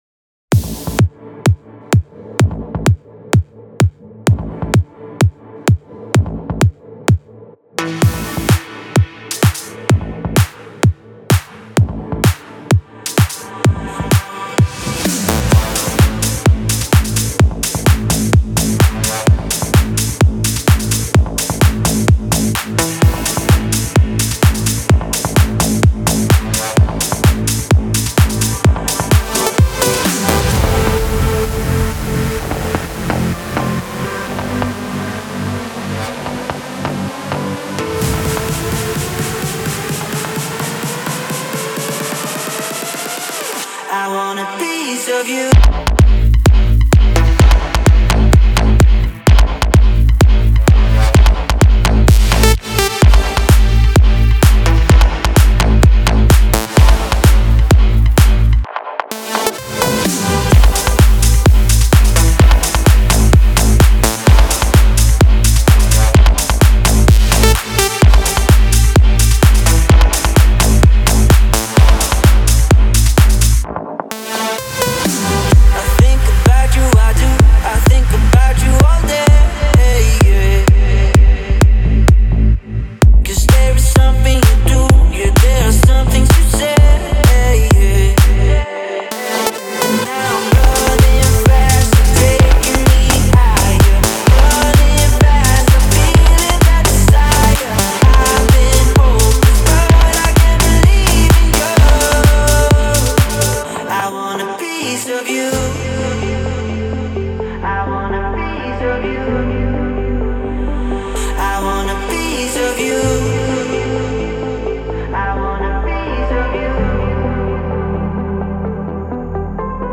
Future Rave Melodic Techno Trance